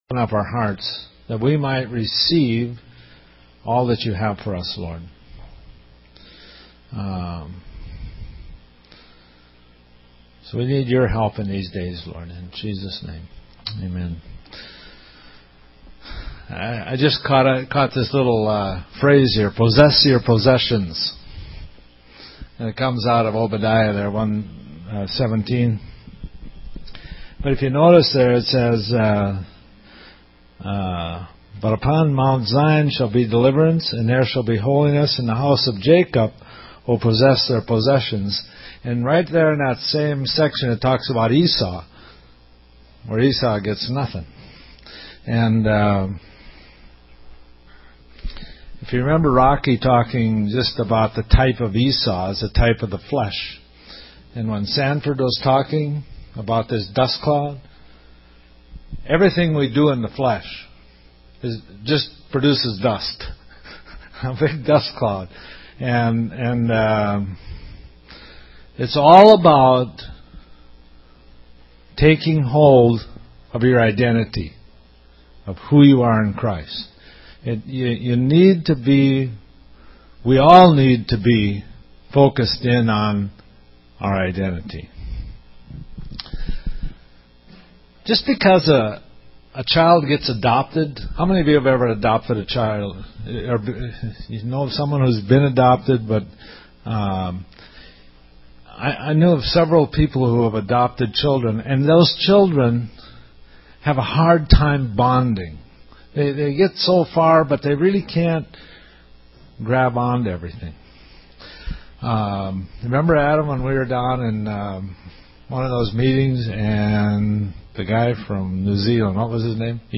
June 6th… 120610-PossessYourPossessions This entry was posted in sermons .